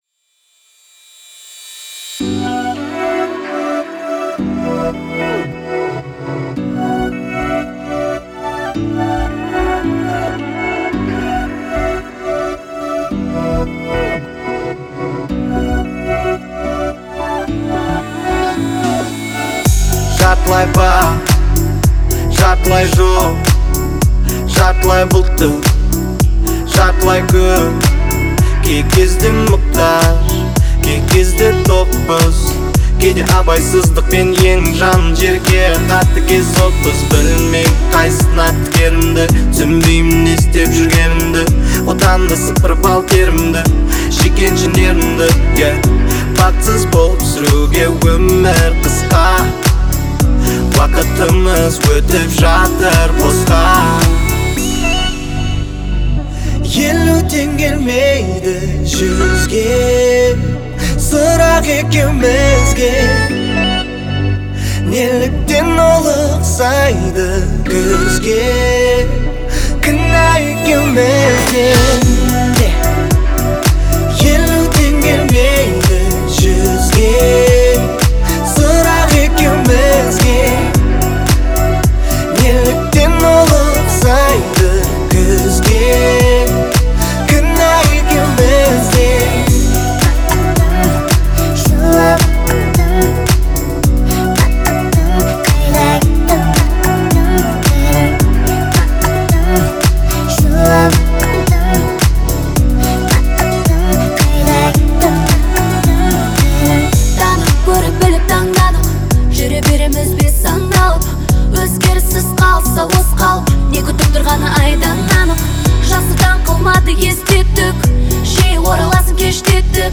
современный казахский поп с элементами R&B